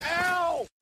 Ow Button 2
ow2.mp3